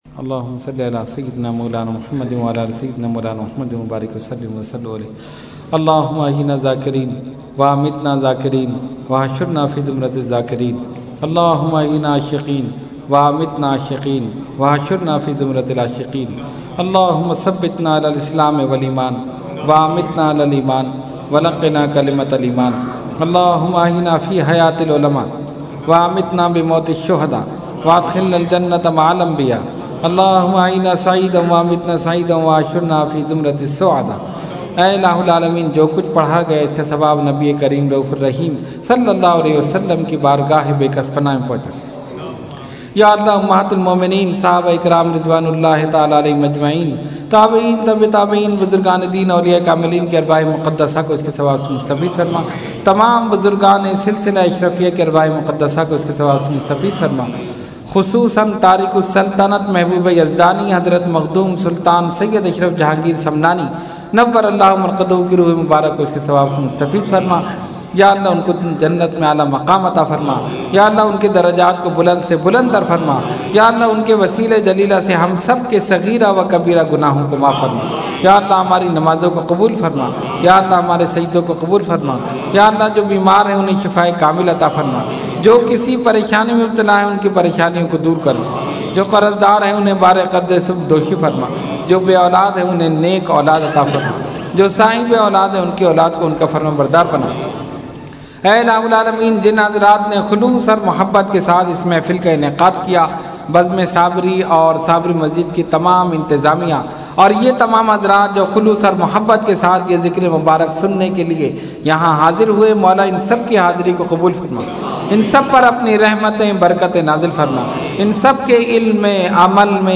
Category : Dua | Language : ArabicEvent : Urs Makhdoome Samnani 16 Dec 2012 Sabri Masjid